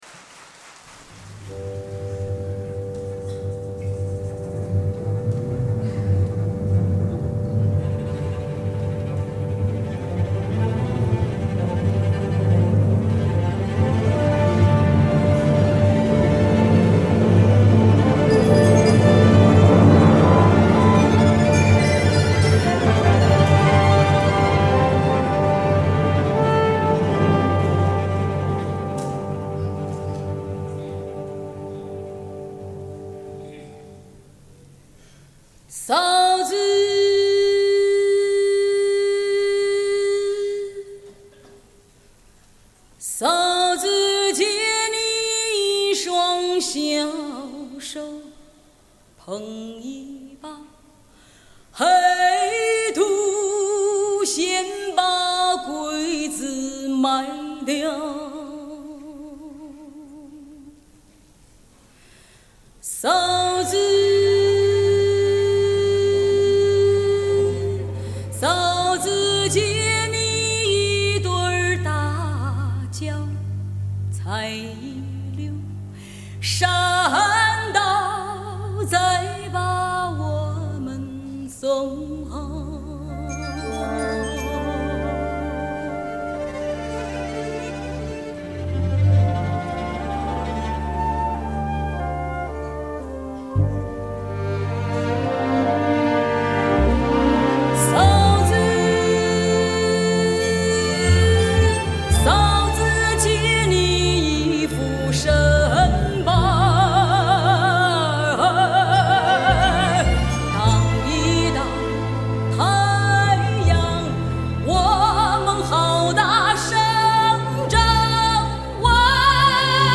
收集了最珍贵的现场录音
交响乐